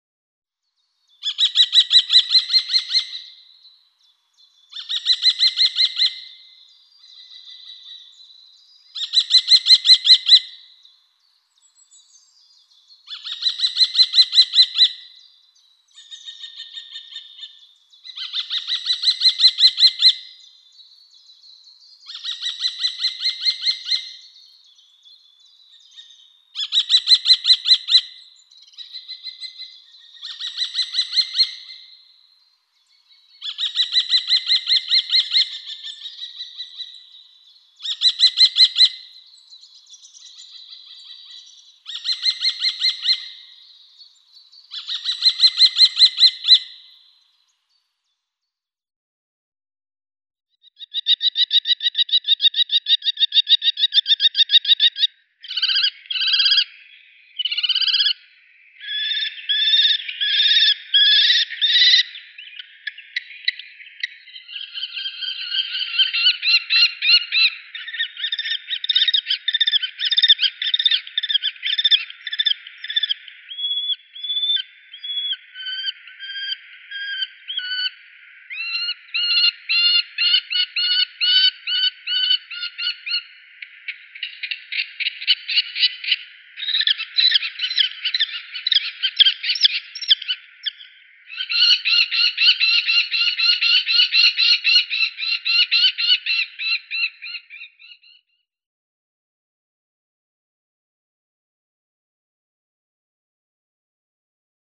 نام انگلیسی: Common Kestrel
نام علمی: Falco tinnunculus
01a.Eurasian Kestrel.mp3